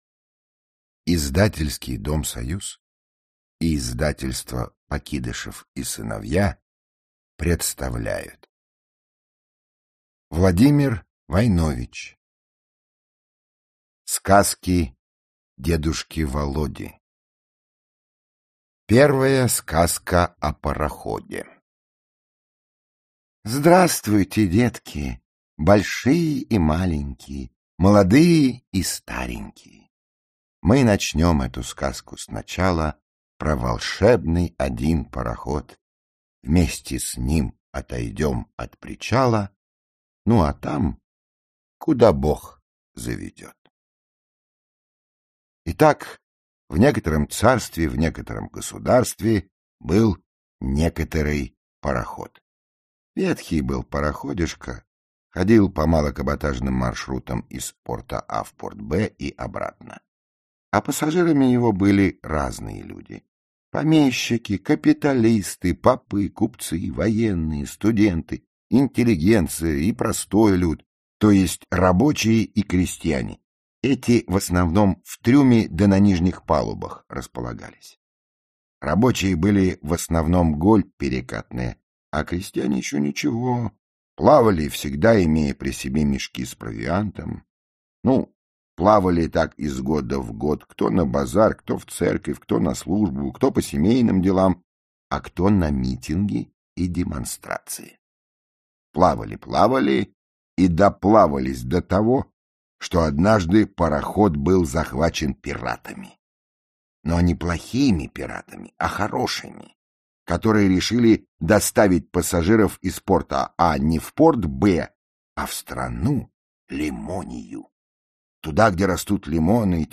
Aудиокнига Сказки дедушки Володи Автор Владимир Войнович Читает аудиокнигу Михаил Горевой.